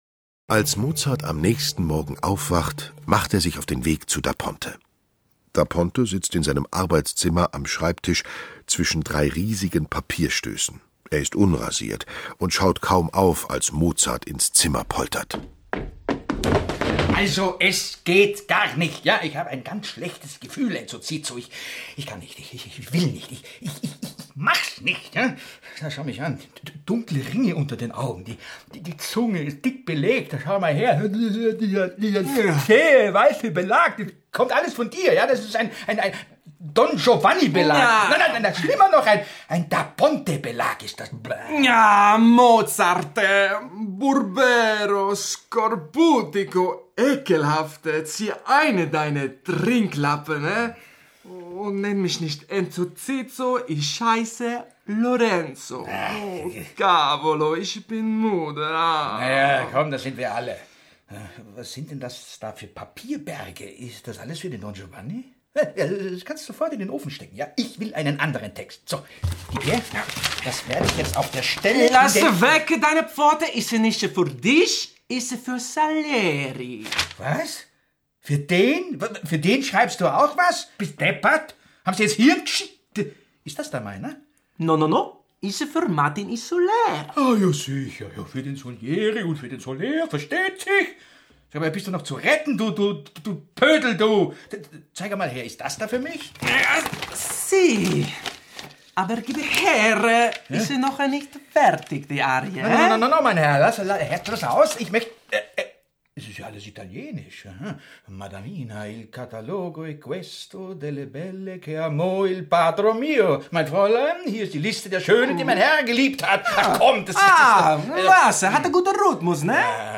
Schlagworte Don Giovanni (Mozart) • Hörbuch; Lesung für Kinder/Jugendliche • klassiche Musik • Mozart • Mozart, Wolfgang A.; Kindersachbuch/Jugendsachbuch • Mozart, Wolfgang Amadeus; Kindersachbuch/Jugendsachbuch • Oper